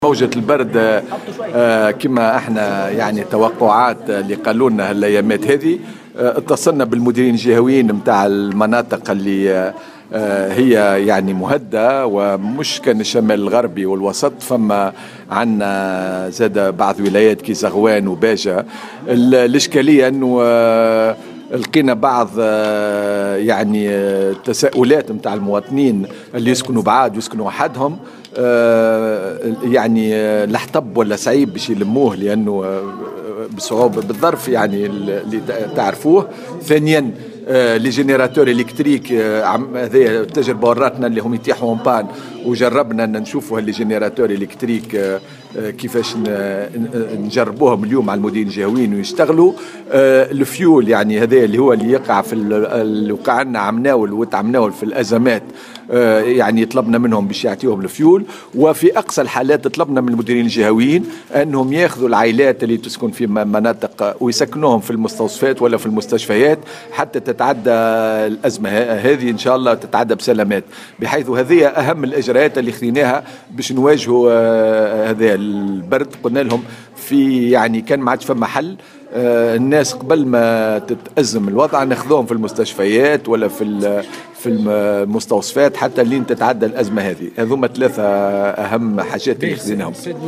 وأضاف الوزير خلال ندوة صحفية تمحورت حول موضوع تمويل قطاع الصحة في تونس بمختلف أشكاله و المشاريع الصحية الجديدة أنه تم التنسيق مع مختلف الإدارات والمستشفيات الجهوية للصحة بالشمال الغربي والوسط وبعض المناطق الأخرى من أجل أخذ الاحتياطات الضرورية وايواء بعض العائلات في المستشفيات والمستوصفات.